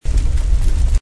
snd_ab_fire.mp3